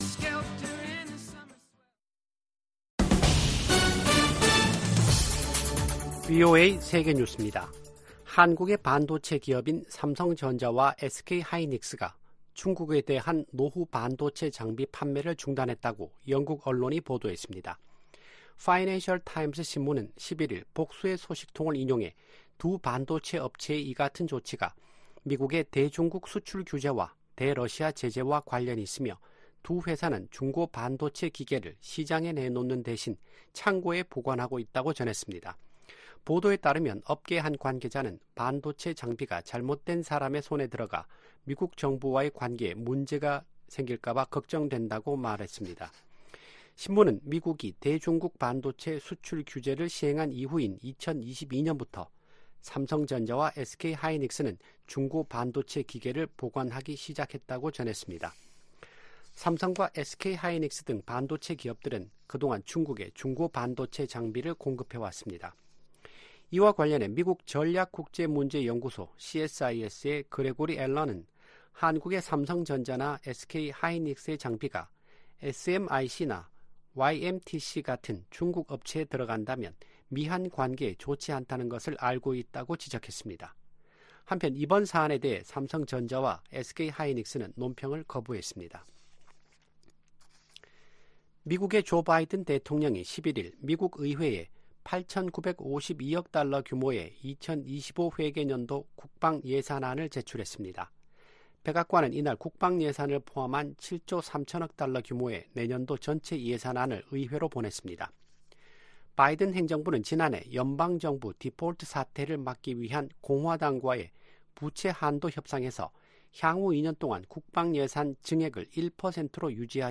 VOA 한국어 아침 뉴스 프로그램 '워싱턴 뉴스 광장' 2024년 3월 13일 방송입니다. 북한과 중국, 러시아, 이란의 협력이 동맹 수준으로 발전하지는 못할 것이라고 미 국가정보국장이 내다봤습니다. 탈북민 구조활동을 벌여 온 것으로 알려진 한국 선교사가 올해 초 러시아 당국에 간첩 혐의로 체포됐습니다. 존 볼튼 전 미 국가안보보좌관은 도널드 트럼프 전 대통령이 재집권할 경우 미북 정상회담이 다시 추진될 수도 있을 것으로 VOA 인터뷰에서 전망했습니다.